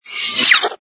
ui_motion_swish.wav